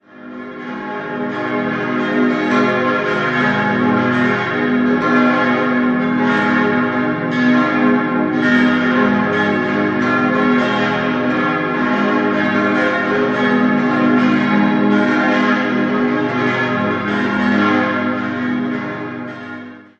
6-stimmiges Geläute: as°-c'-es'-f'-as'-c'' Alle Glocken wurden von der Gießerei Rüetschi in Aarau gegossen: Die kleinste bereits 1951, die fünf größeren im Jahr 1959.